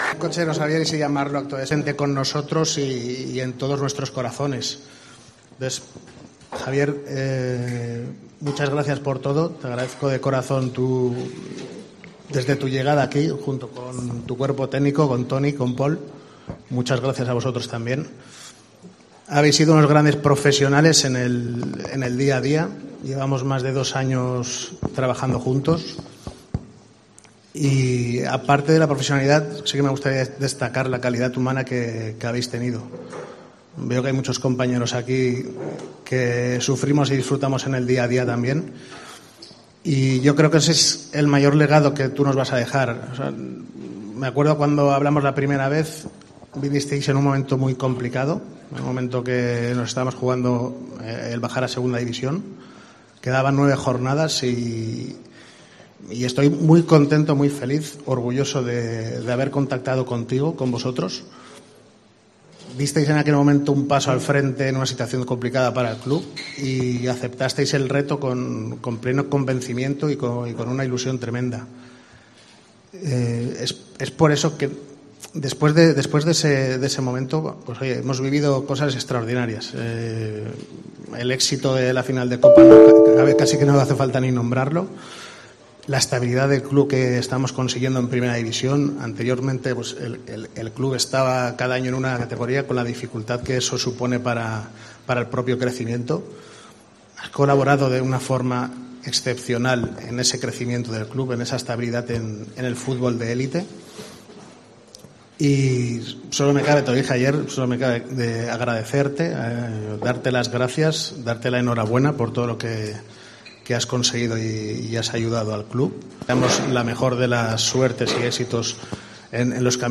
Javier Aguirre, emocionado en su despedida: "Siento el calor de la afición"
En ese momento se ha interrumpido con la emoción y ha finalizado el acto con un aplauso.